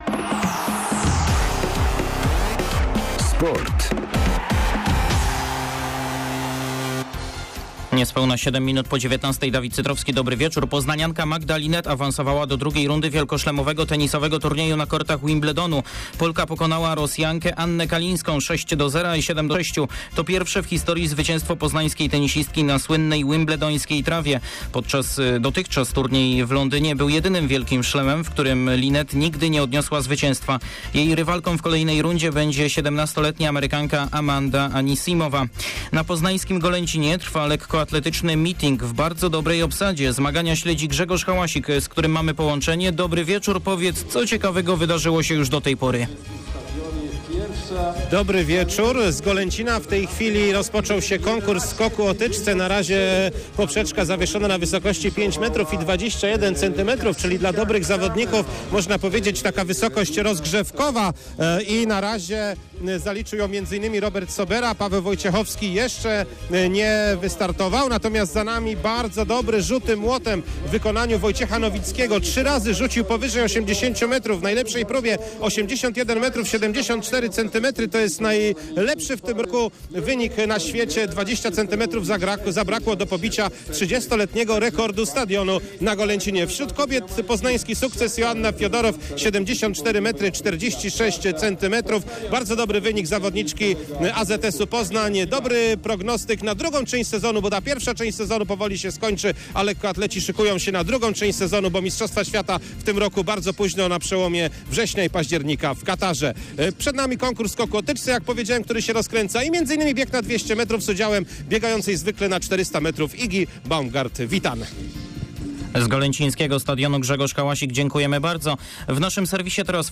02.07. serwis sportowy godz. 19:05
W naszym serwisie meldunek live prostu z obiektu na Golęcinie, gdzie odbywają się duże lekkoatletyczne zawody. Ponadto także o nowym stylu Lecha.